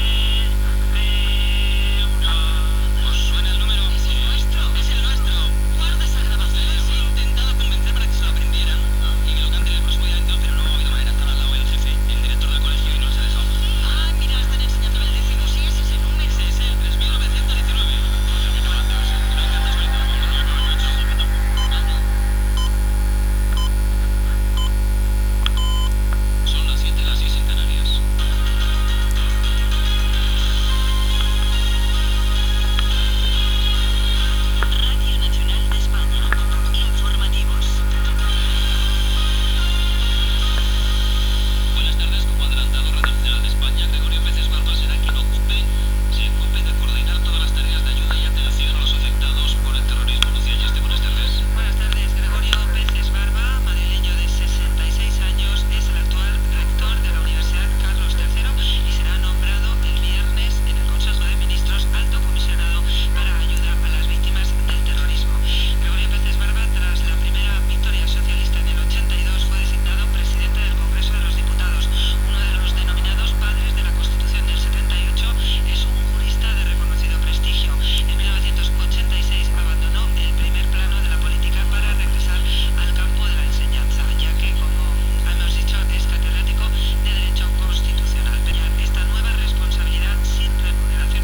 Aquí os presento mi primera radio construida componente a componente en el año 2004. Era tan singular porque, aunque no os lo creais, funcionaba sin pilas!, eso sí, tan solo captaba una emisora (RNE), la mas potente, el chisporroteo del choque de las ondas contra la antena le servía de energía. Ni siquiera necesitó de un triste transistor, todo con un diodo de Germanio, así sonaba...